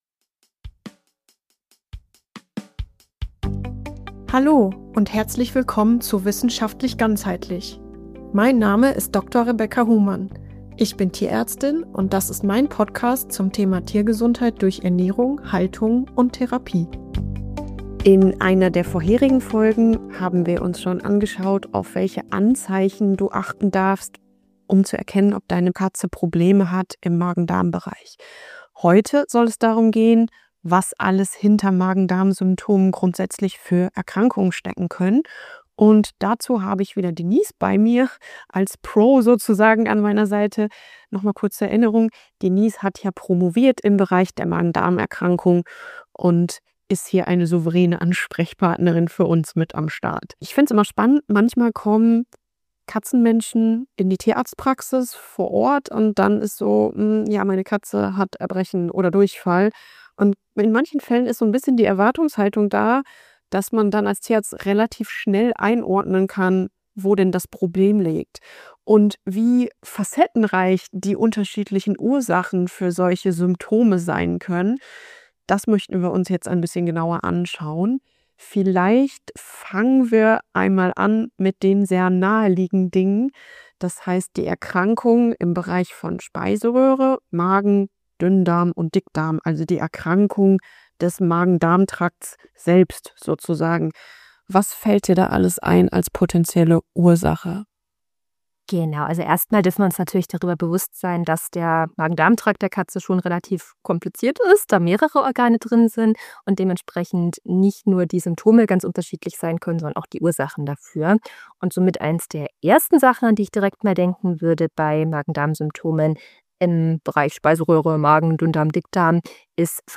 In dieser Folge von Wissenschaftlich Ganzheitlich spreche ich gemeinsam mit Tierärztin